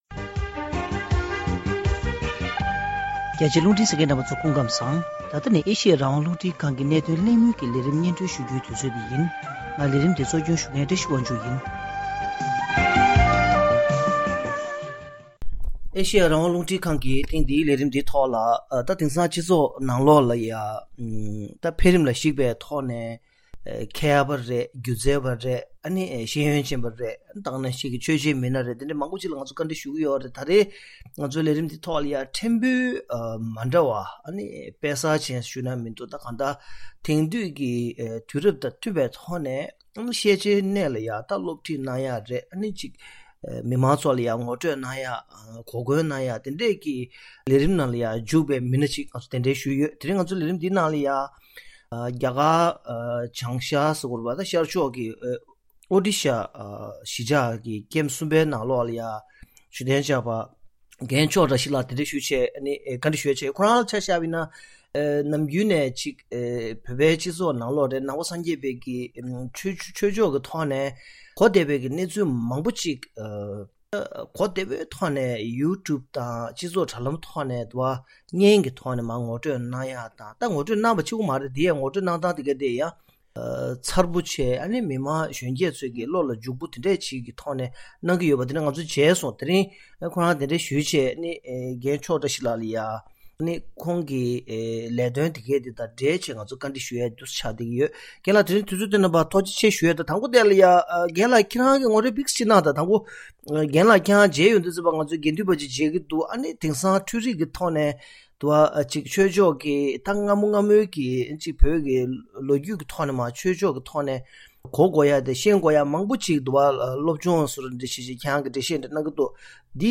དེ་རིང་གནད་དོན་གླེང་མོལ་གྱི་ལས་རིམ་ནང་།